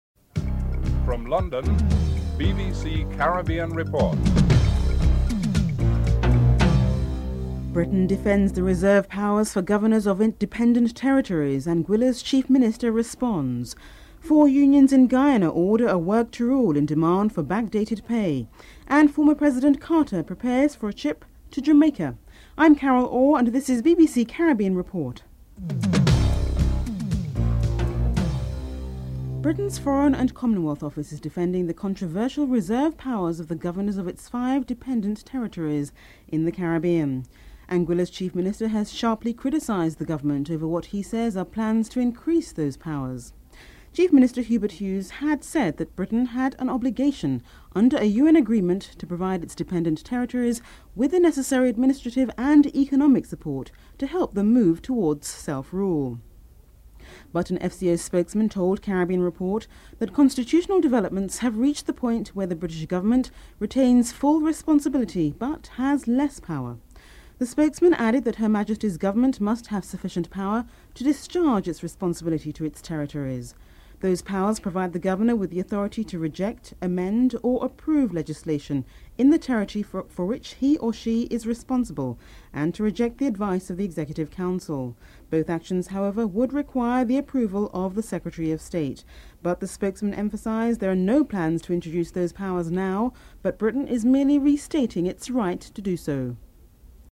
Britain’s Foreign and Commonwealth Office is defending the controversial reserve powers of the governors of its five dependent territories in the Caribbean. Anguilla’s Chief Minister Hubert Hughes strongly criticises the government in what he says are plans to increase those power. An FCA spokesman also discusses the constitutional aspect of it and gives Britain’s present position. The next segment discusses the introduction of VAT in Barbados and its effects.